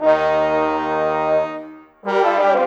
Rock-Pop 07 Brass 05.wav